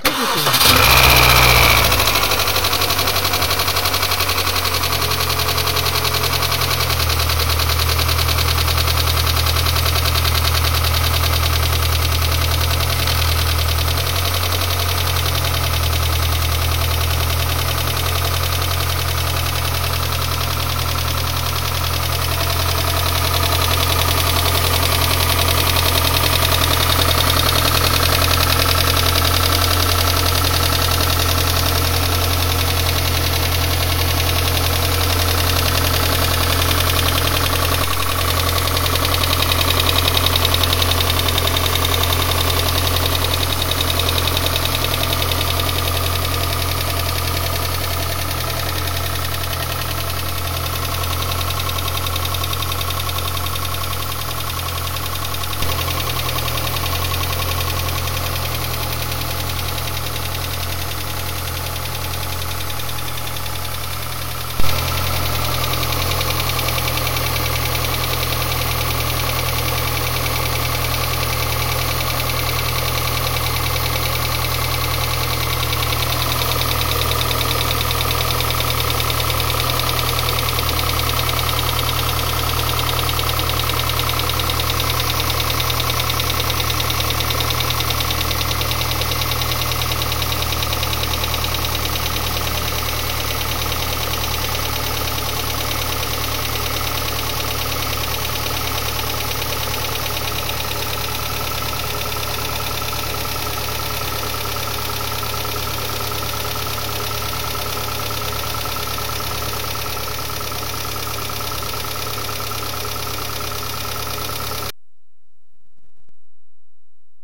29.始動時にエンジン異音（ガタガタ、カンカン）
エンジン始動時に⇒ガタガタ、カンカン音⇒２〜３分で静かになる。
異音 最初大きく、段々小さくなる。
mini-engion-v.wav